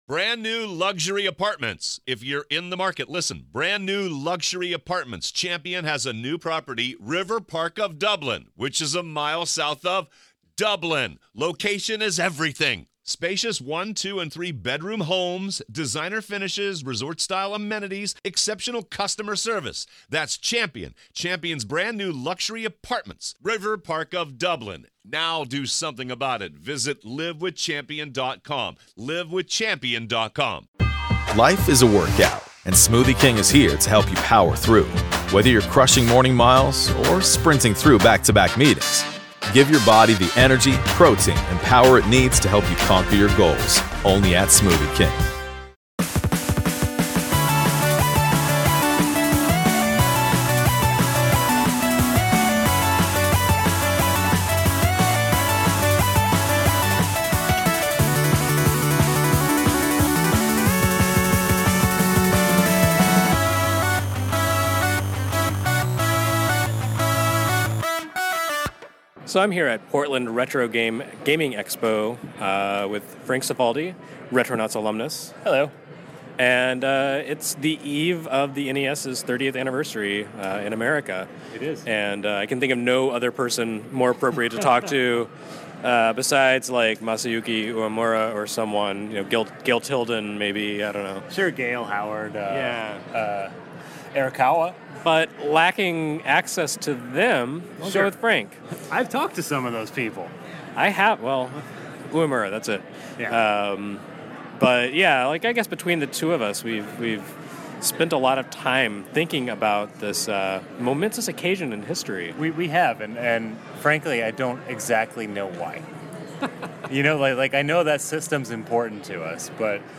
Live at Portland Retro Gaming Expo